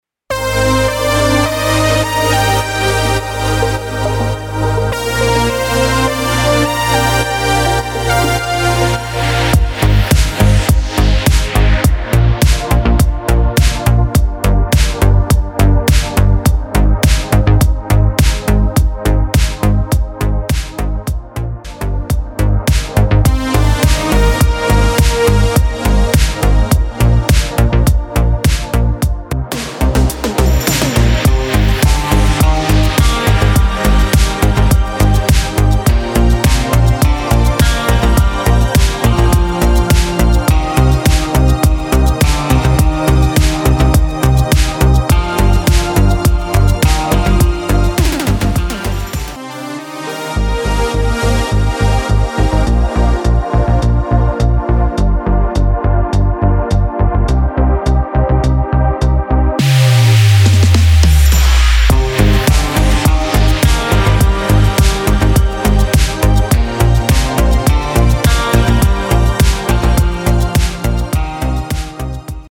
Tonacija: cm-dm-bm (su bekais ir be)